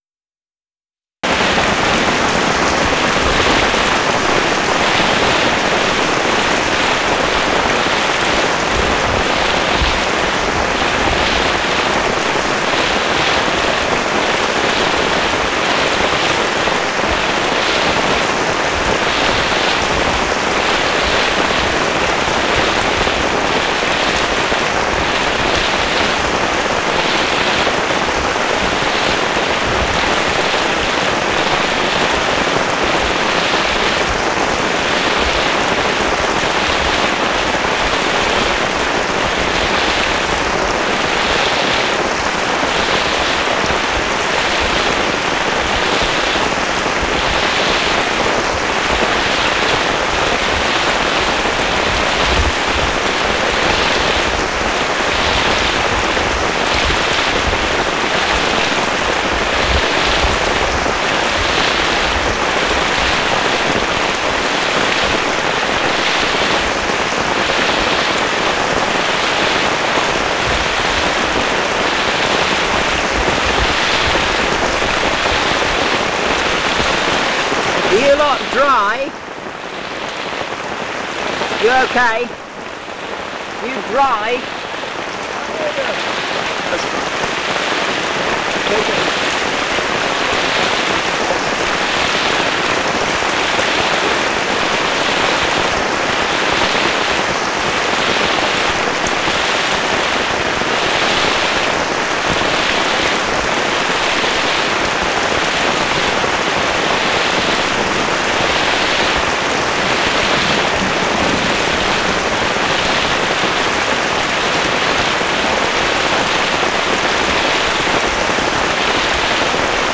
Hog's Back camp, late afternoon - why it's called a rain-forest!
Non-specimen recording: Soundscape